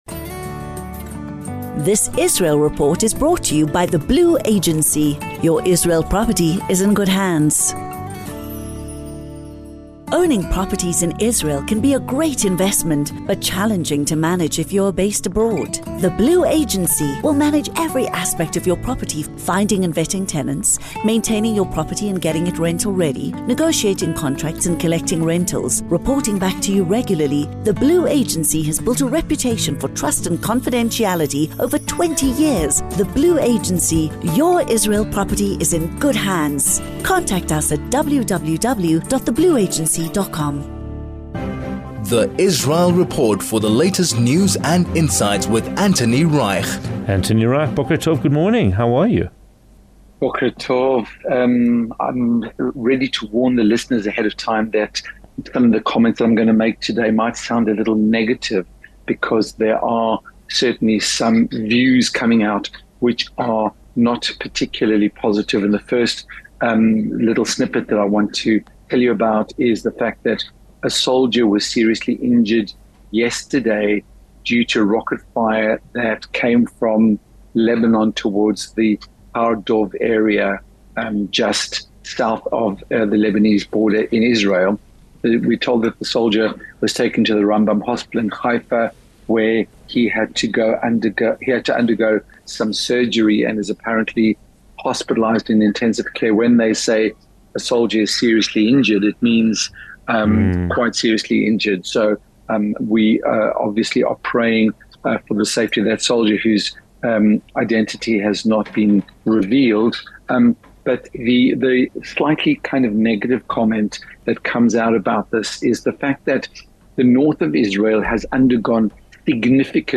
Israel Report